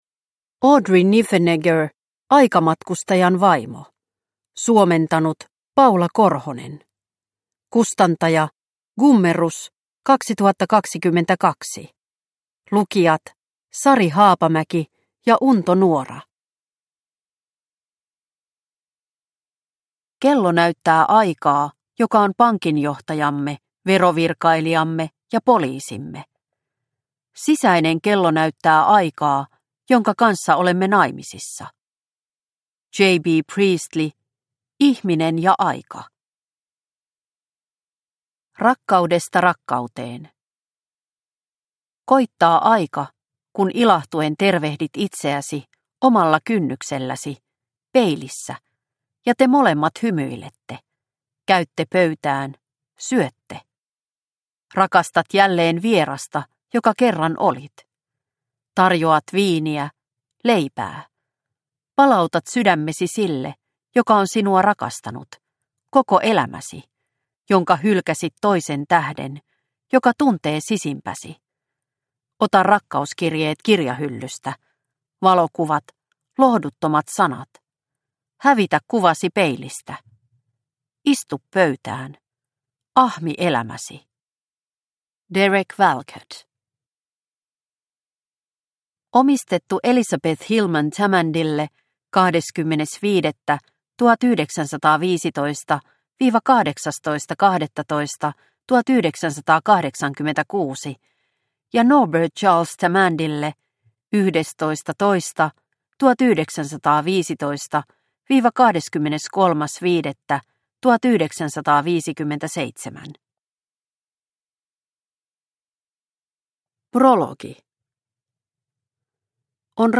AIkamatkustajan vaimo – Ljudbok – Laddas ner